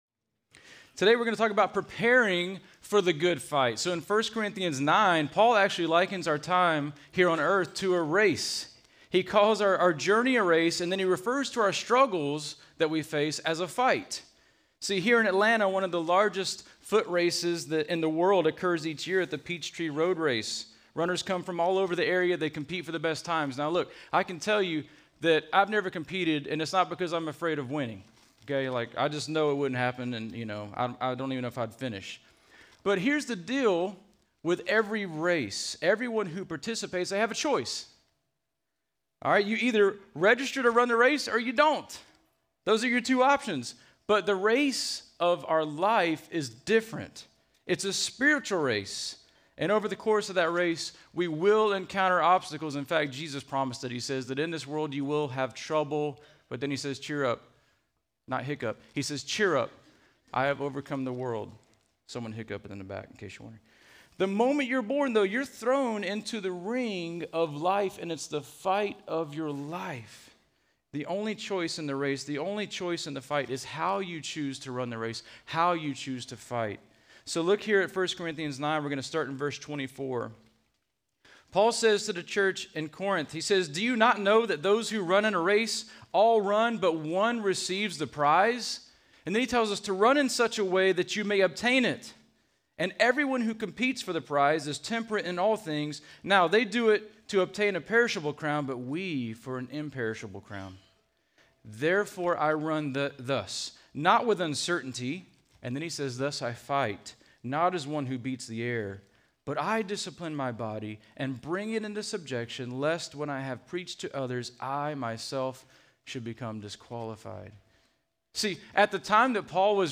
Home » Sermons » Preparing for the Fight
Conference: Youth Conference